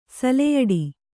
♪ saleyaḍi